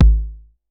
Tr8 Kick 03.wav